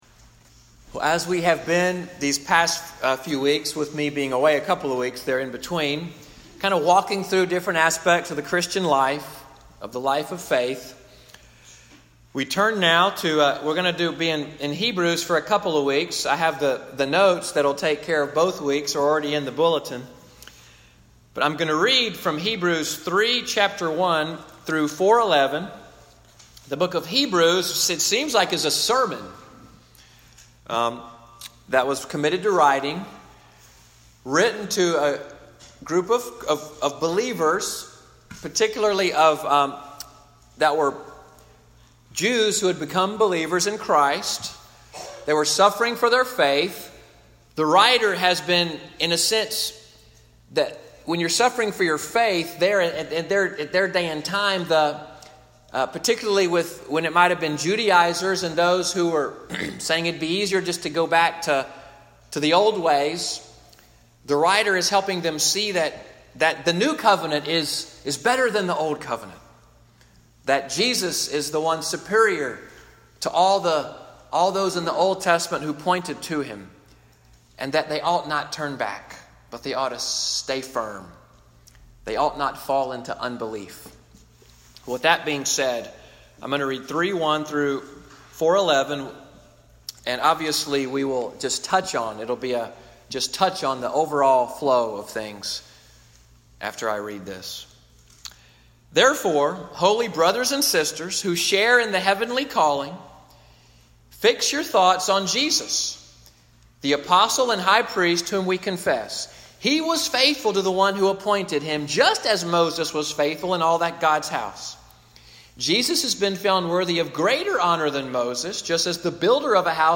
Sermon Audio from the Worship Service of Little Sandy Ridge Presbyterian Church of Fort Deposit, Alabama.